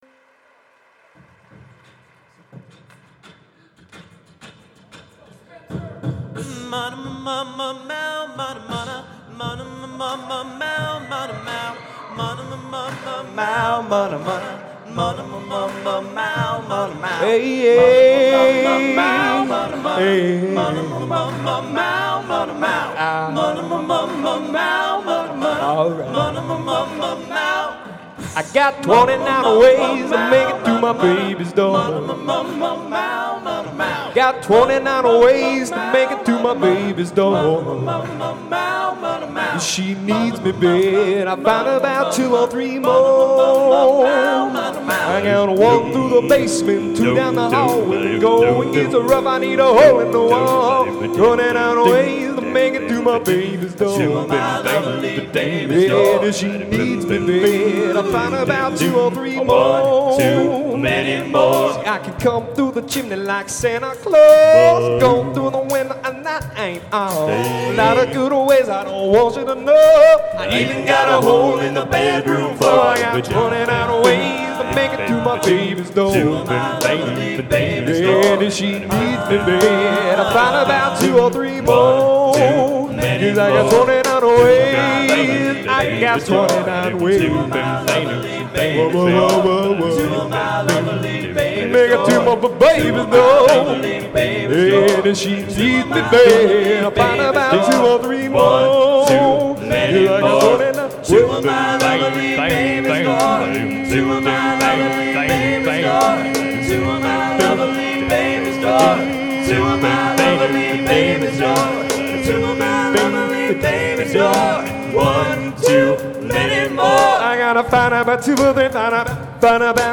Genre: Popular / Standards | Type: Specialty